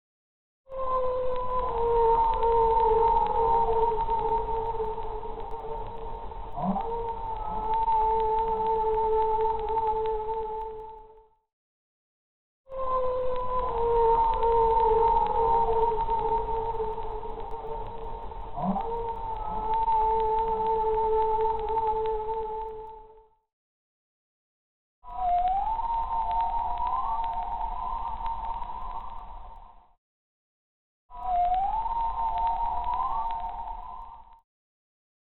Want to hear the original audio file of the Humpback's song that inspired BELOW and BENEATH, as picked up by the NOAA hydrophone? Click the icon to the right!
Below-Beneath-Shapiro-whalesong-raw.mp3